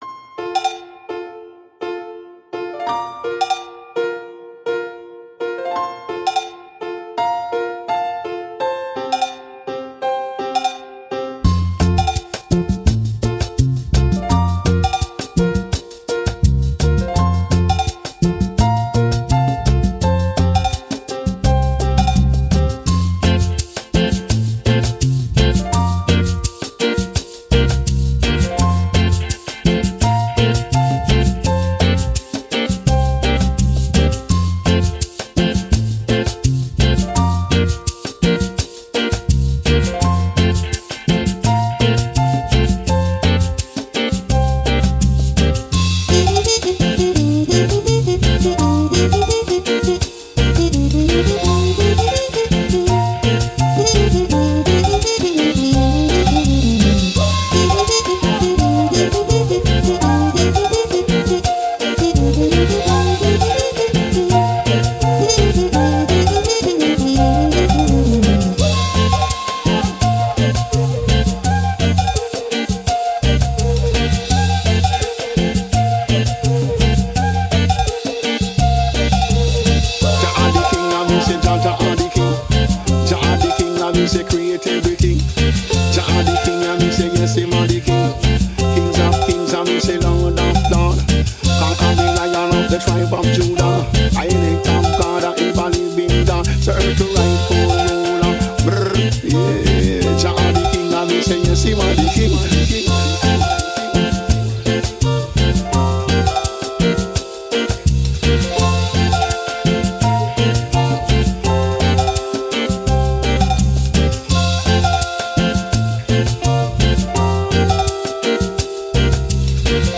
Dub, Reggae, Positive, Uplifting